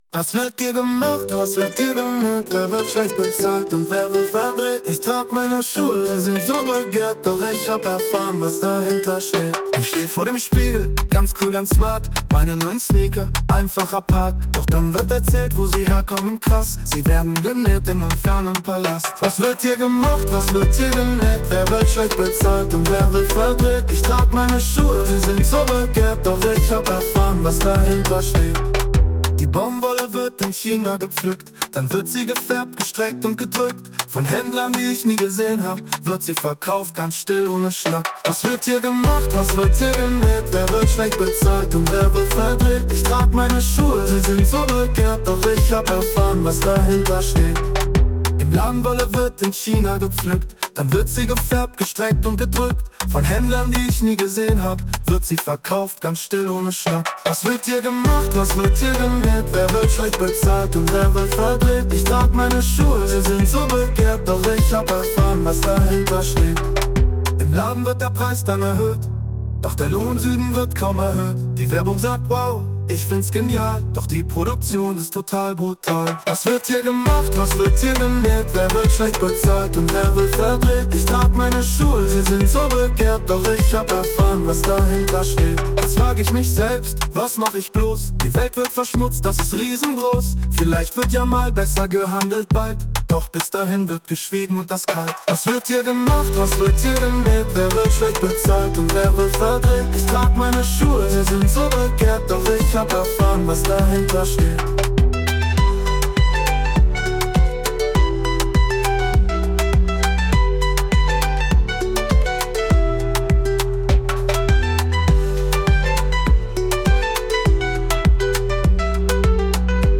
Cette chanson peut ensuite être mise en musique grâce à l’IA.